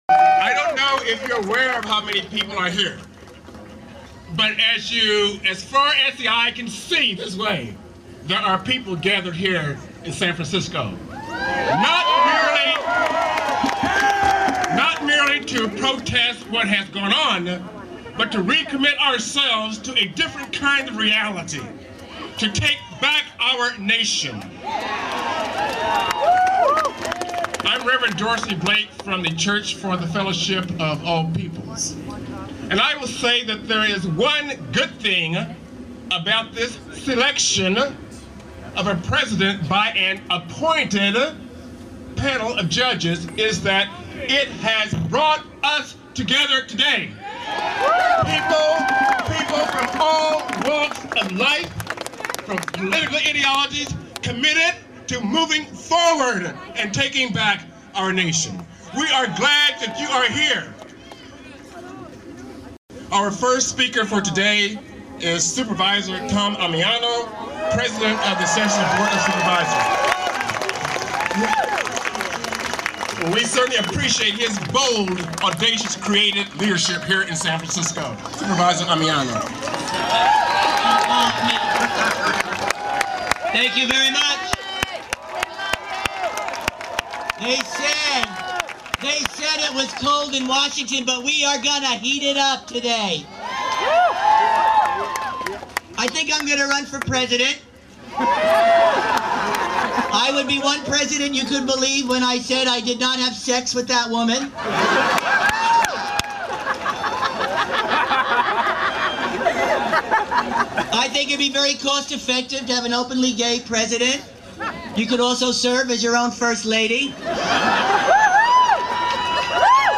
Supervisor Tom Ammiano spoke at the J20 counter-inaugural in San Francisco.